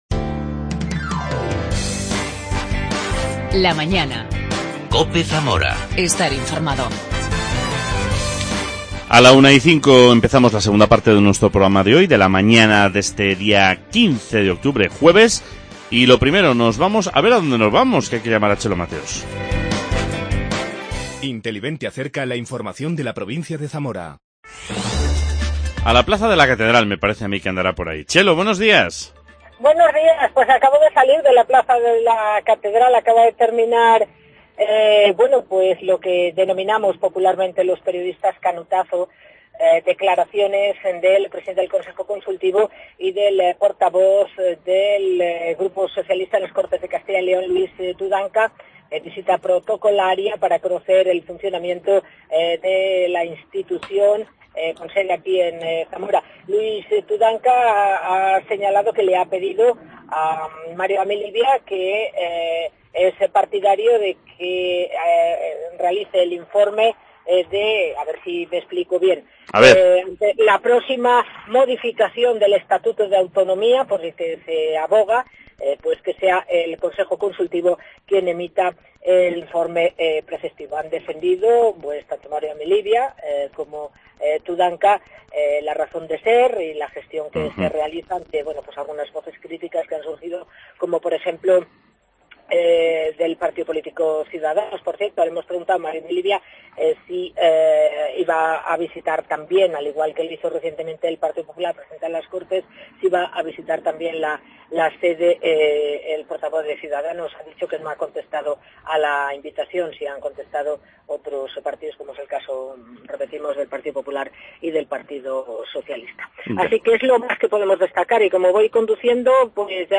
charla